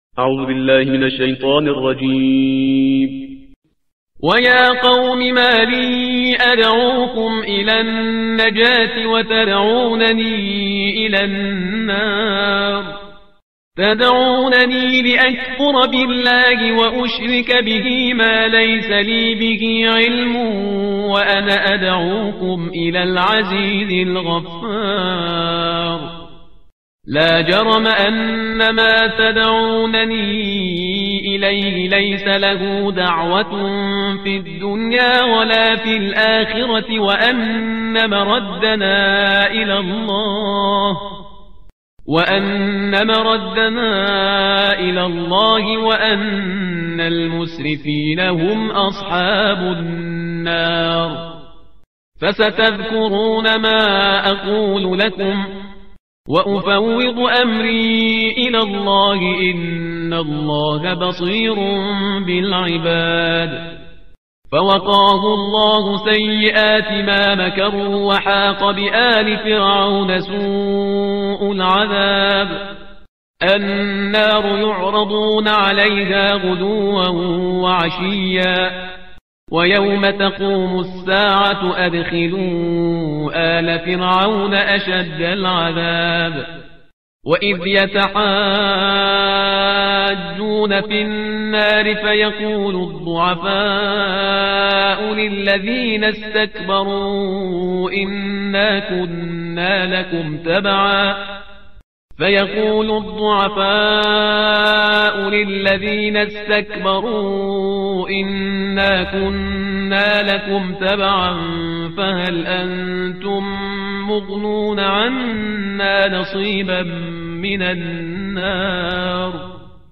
ترتیل صفحه 472 قرآن با صدای شهریار پرهیزگار